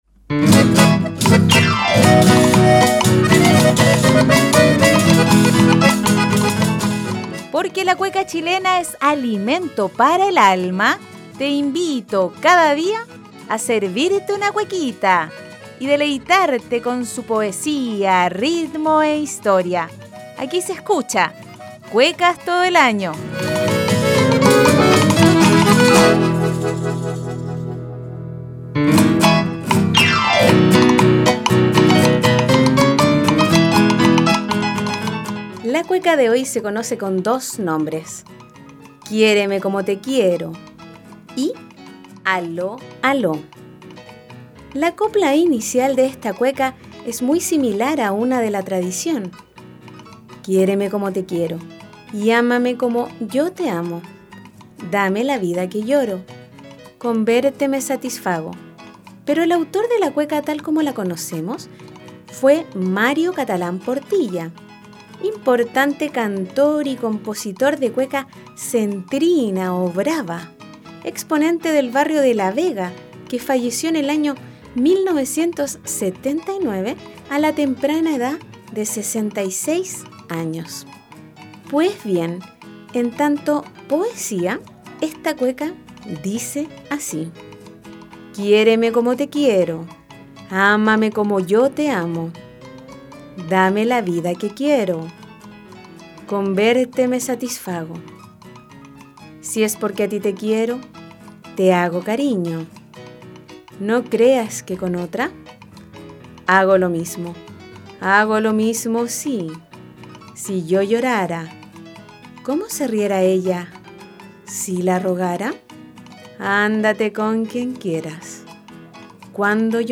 En el programa 1 de la primera temporada de “Cuecas todo el año”, nos deleitamos con la cueca “Quiéreme como te quiero” o “Aló Aló”. Además de apreciar su poesía y conocer un poco más de su contexto de creación, la escucharemos en dos versiones: la clásica de Mario Catalán junto al Dúo Rey Silva y una mucho  más contemporánea en la voz del Lote Cuequero.